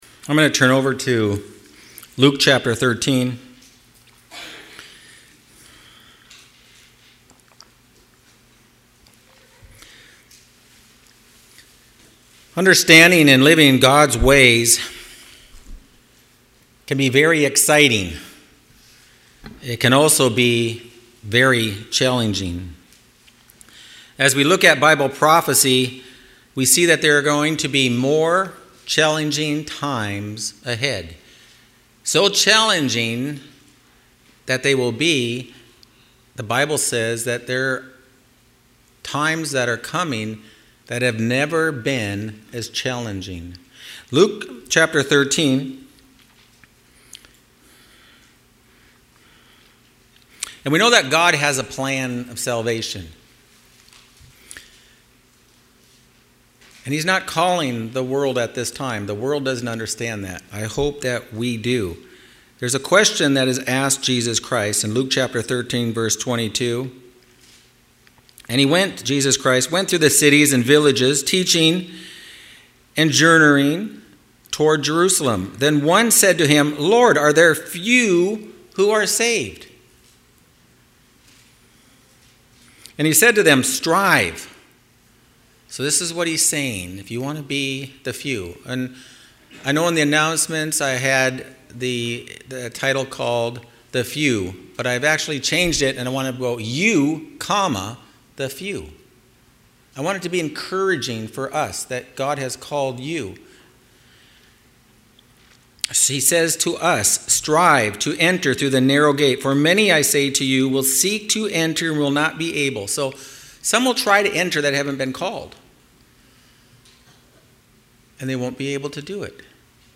Sermons
Given in Phoenix East, AZ